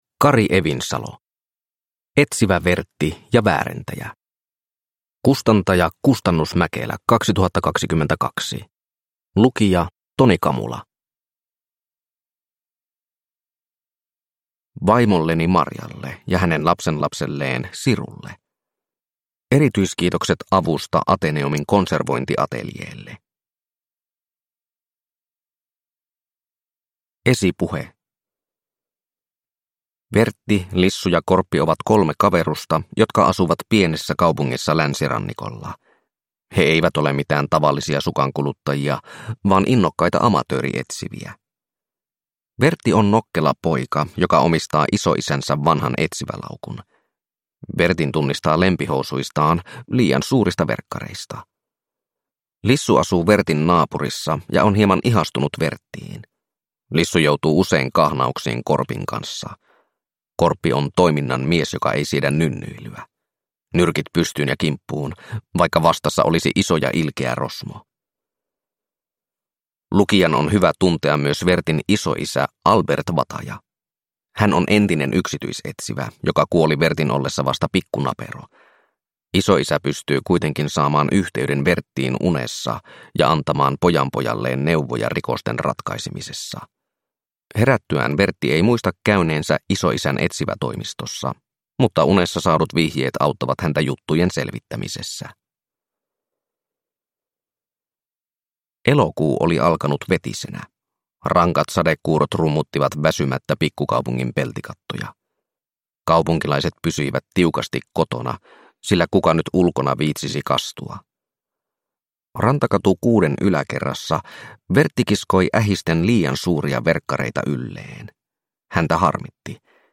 Etsivä Vertti ja väärentäjä – Ljudbok – Laddas ner